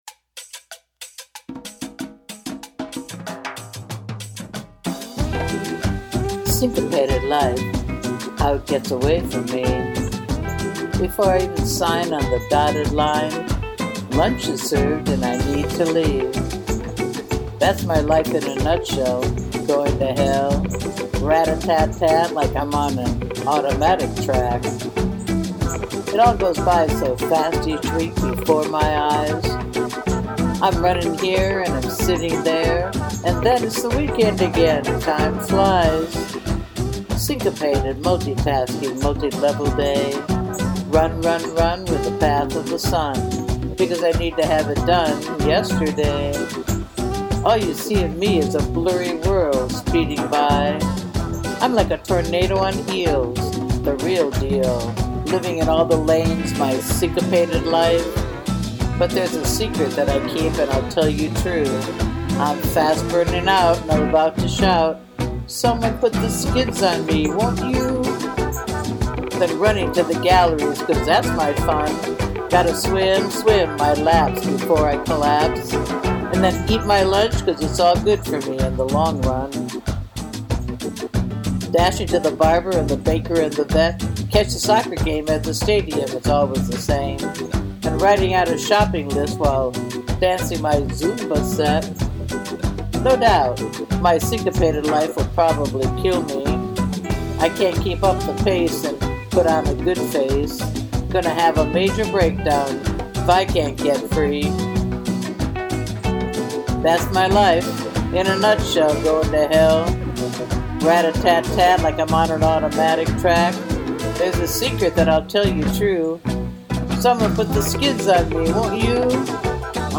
Click Play to hear me recite it to “Chill Latin Salsa Café Dinner Lounge Instrumental Music”
And all the inner rhymes reinforce the speed and frantic pace of the words; so impressive!
And your voice has this beautiful ease and peace to it, makes the listener zoom into your words 😊.
I was looking in bossa nova, but I was wrong..just good old Salsa Tropical and I was lucky to find this piece in a compilation of 5 hours..this one stood out:) Anyway so happy you loved how it came out:):) I used a different kind of voice FX called Radio Voice..I guess it worked:):) See you on Zoom soon hahaha:):) maybe after your tennis game :):)